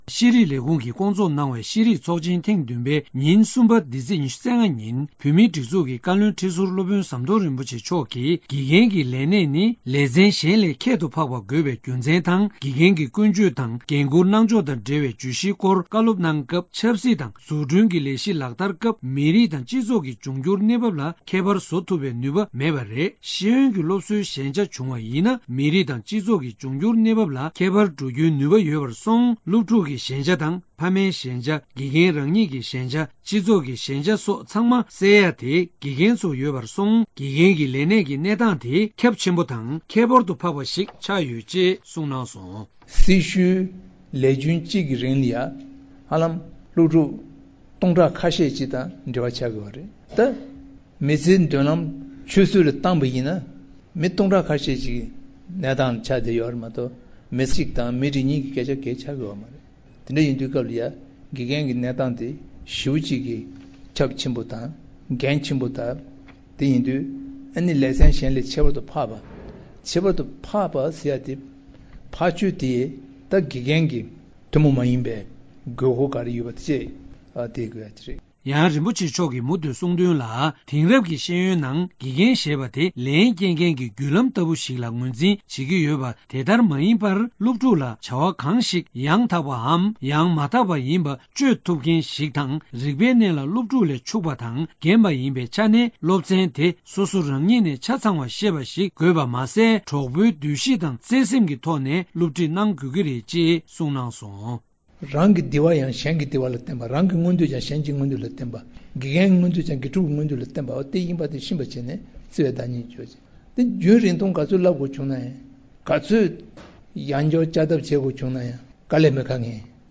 དེ་ཡང་ཤེས་རིག་ལས་ཁུངས་ཀྱིས་སྐོང་ཚོགས་གནང་བའི་ཤེས་རིག་ཚོགས་ཆེན་ཐེངས་བདུན་པའི་ཉིན་གསུམ་པ་འདི་ཚེས་༢༥ཉིན།